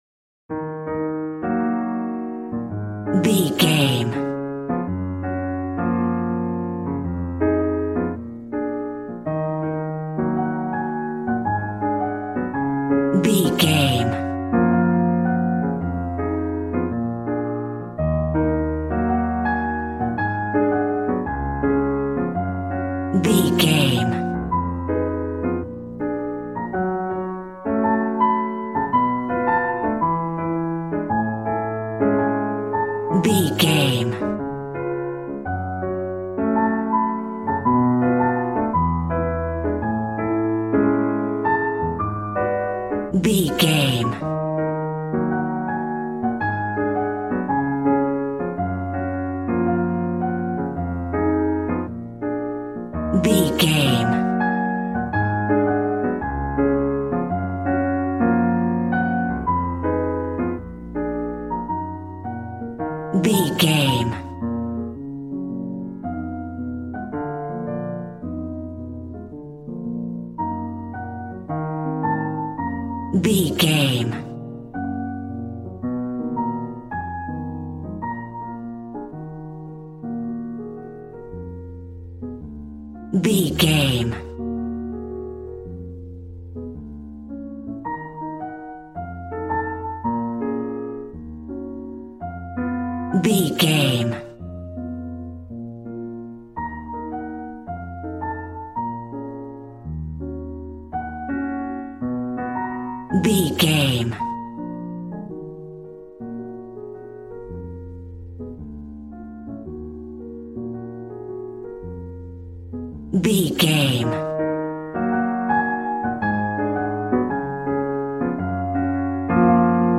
Smooth jazz piano mixed with jazz bass and cool jazz drums.,
Aeolian/Minor
B♭
smooth
piano
drums